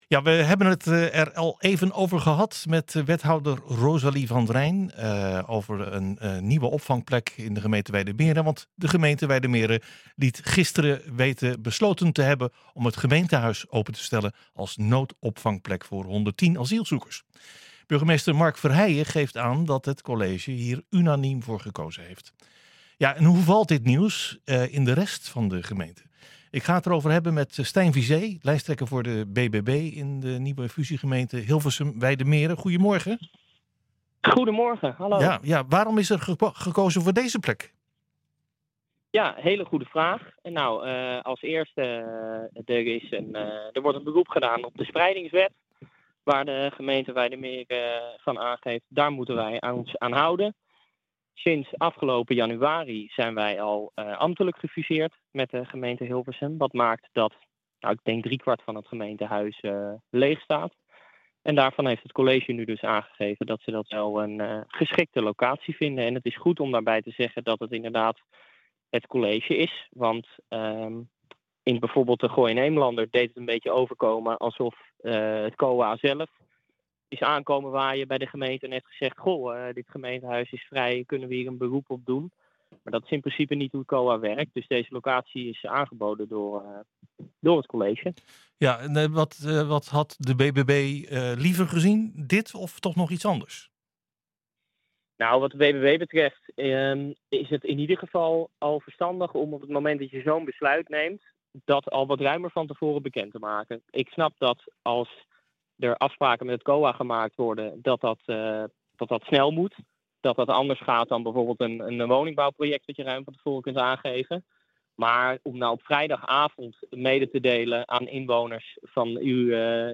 Hoe valt dit nieuws in de rest van de gemeente? Wij spreken met